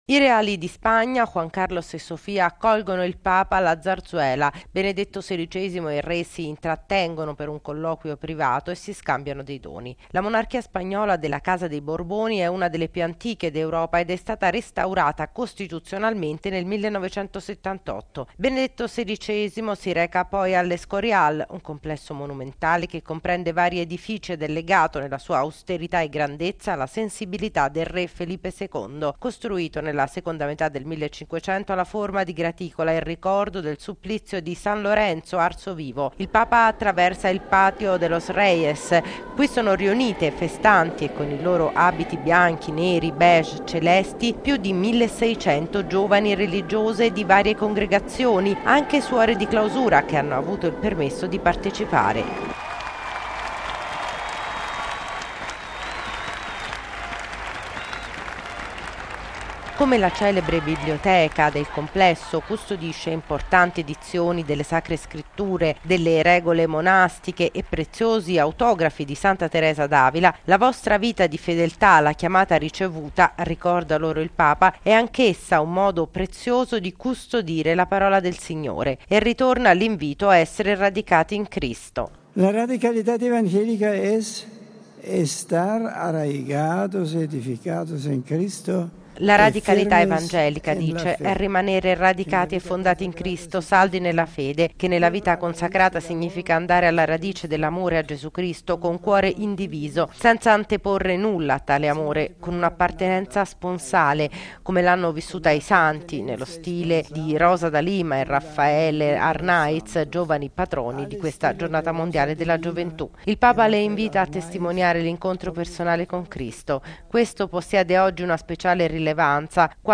(musica)